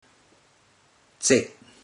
Click each Romanised Teochew word to listen to how the Teochew word is pronounced.
zeig1 (Variant: zeg1) ig0 (if not the first digit)